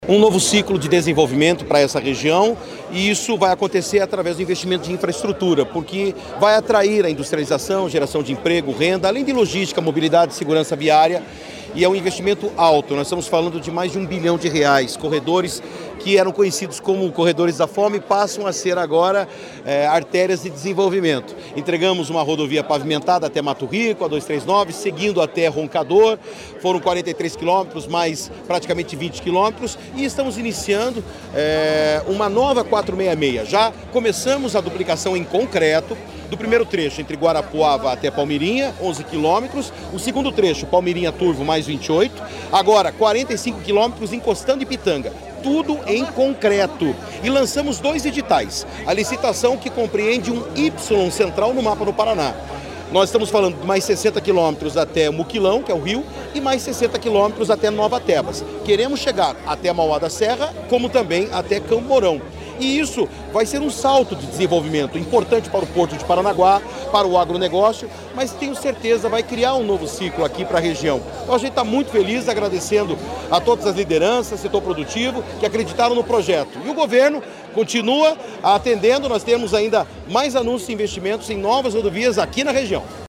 Sonora do secretário de Infraestrutura e Logística, Sandro Alex, sobre a duplicação integral em concreto entre Guarapuava e Pitanga